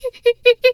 pgs/Assets/Audio/Animal_Impersonations/hyena_laugh_short_01.wav at master
hyena_laugh_short_01.wav